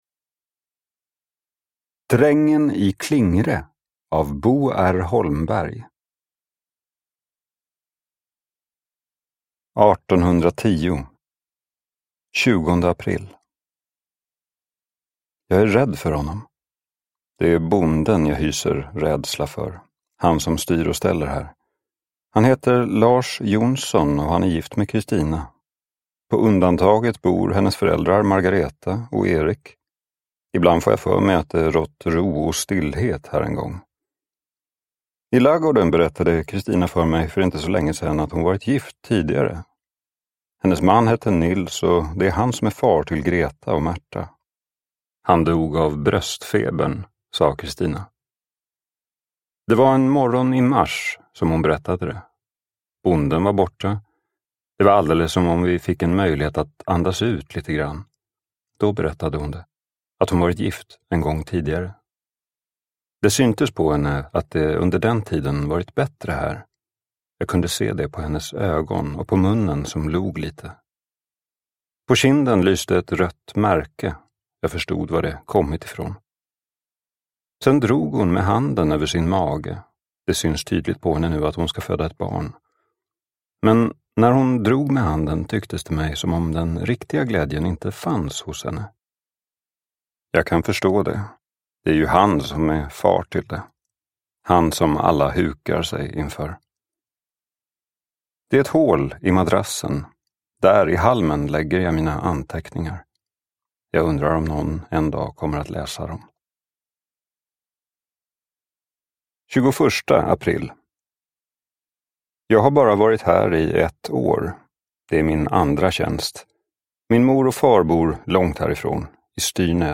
Drängen i Klingre : Andreas Olofssons dagbok 1810-1811 – Ljudbok – Laddas ner